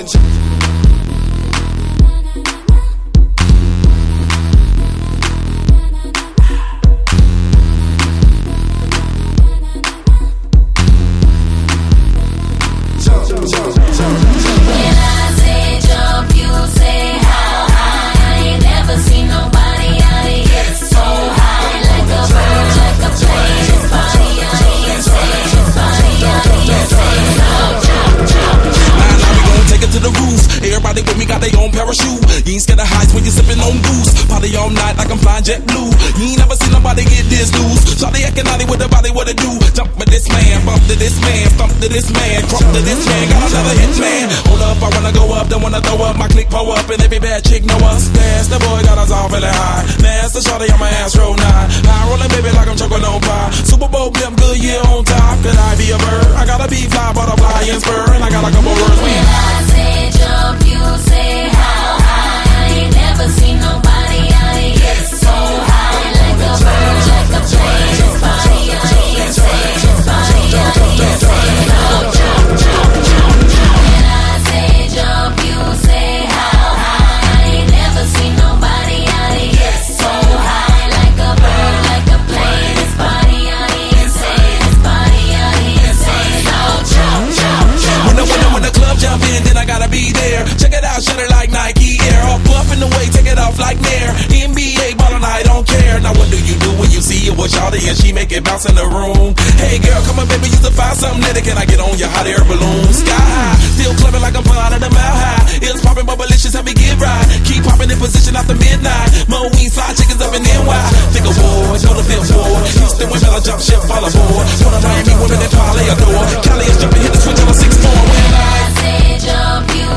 Категория: RnB,Rap,Hip-Hop